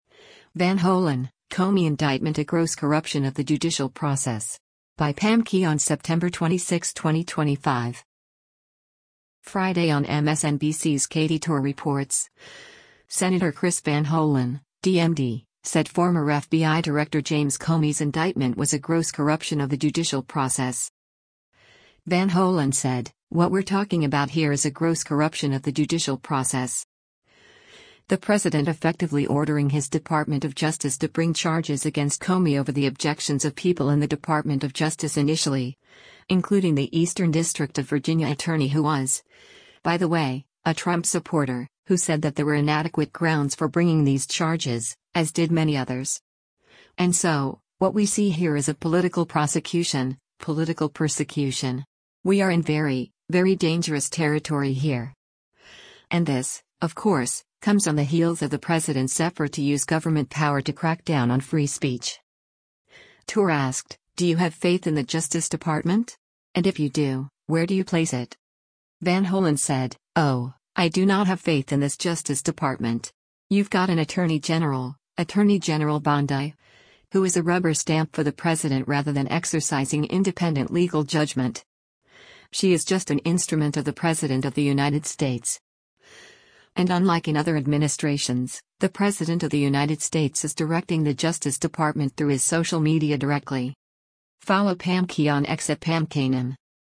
Friday on MSNBC’s “Katy Tur Reports,” Sen. Chris Van Hollen (D-MD) said former FBI Director James Comey’s indictment was a “gross corruption of the judicial process.”
Tur asked, “Do you have faith in the Justice Department? And if you do, where do you place it?”